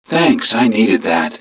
mission_voice_idmm016.wav